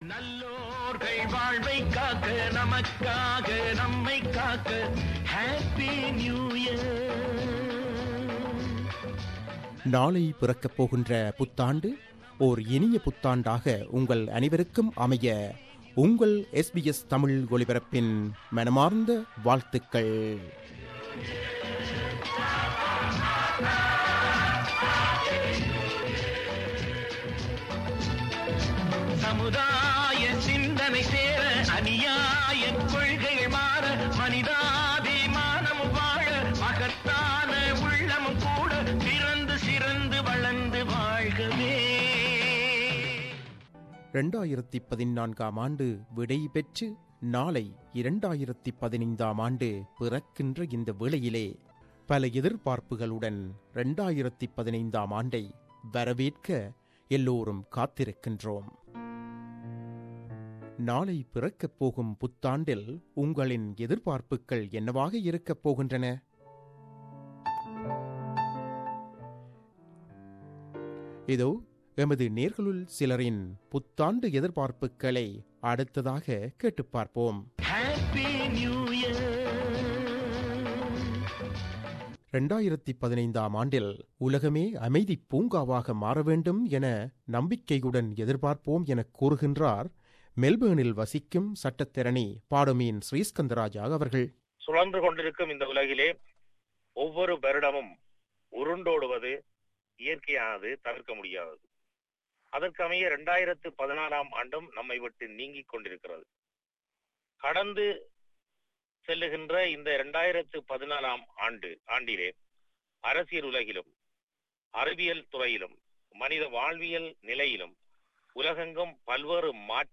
2015 புதுவருடத்துக்கான தமது எதிர்பார்ப்புக்களையும் விருப்பங்களையும் நமது நேயர்கள் சிலர் நம்முடன் பகிர்ந்து கொள்கிறார்கள். அத்துடன் ஒரு புத்தம் புதிய பாடலும் ஒலிக்கவுள்ளது.